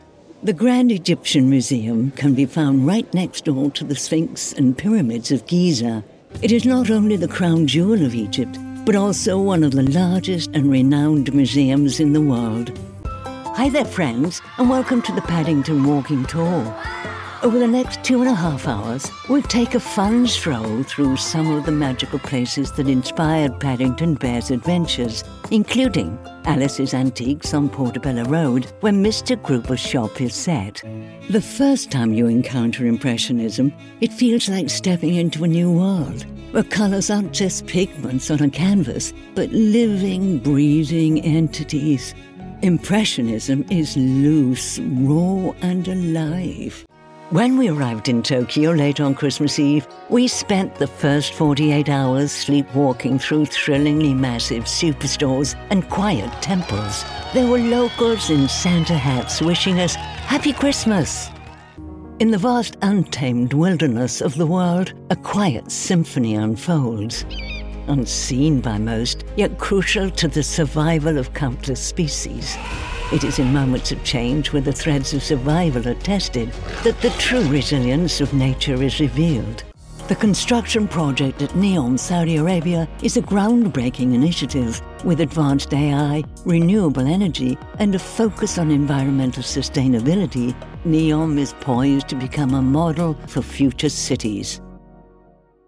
Female
English (British)
Am a professional voice actor with a unique sound - relaxed yet formal, playful yet serious.
Narration
An International Mix